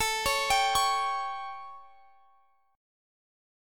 Listen to A7 strummed